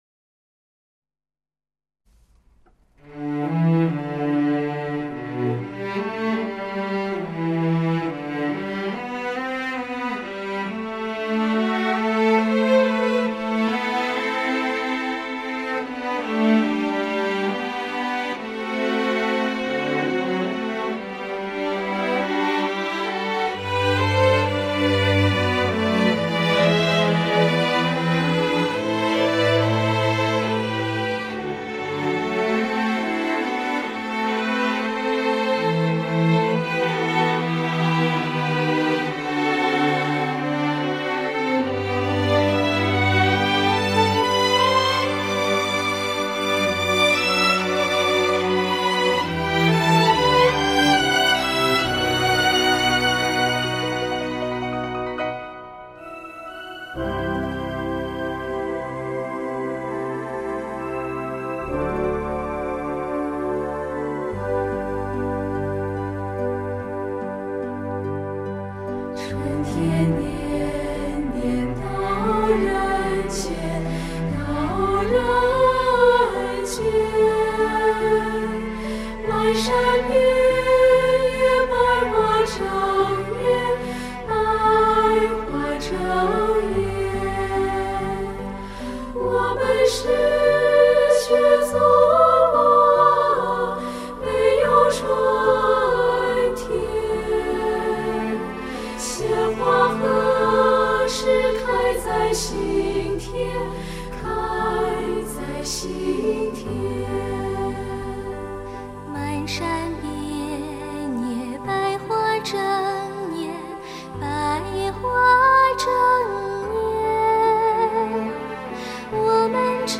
不用MIDI的感觉就是不同
松香味、牛筋味十足，很是HIFI！！